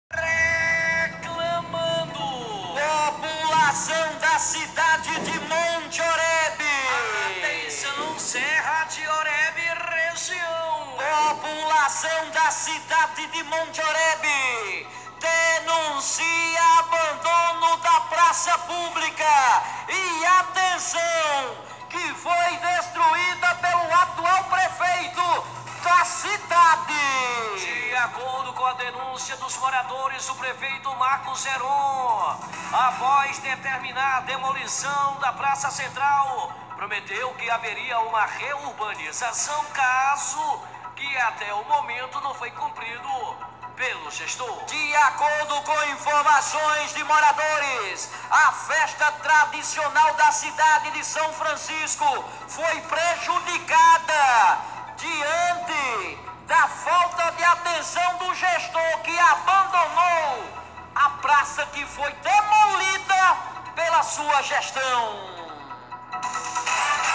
As informações repercutidas aqui pelo Radar Sertanejo foram veiculadas pela Rádio Alto Piranhas da cidade de Cajazeiras.